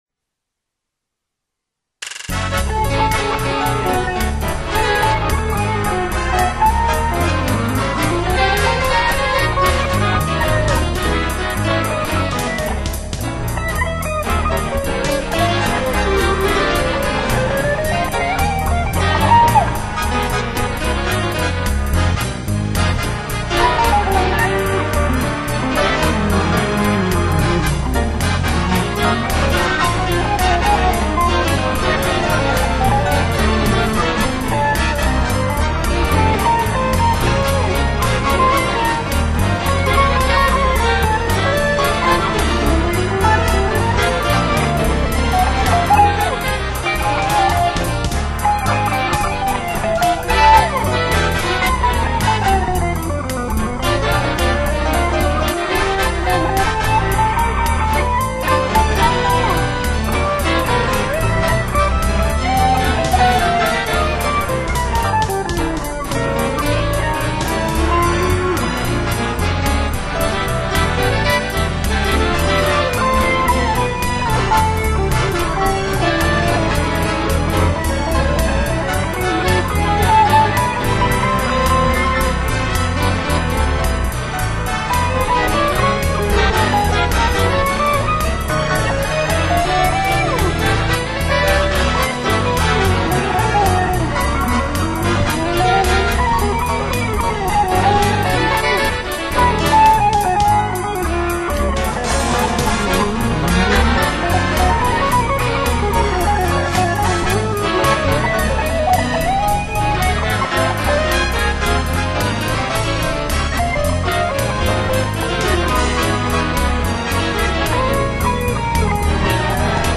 335 Gibson / GR50 Roland
5'20 wma stéréo lo-fi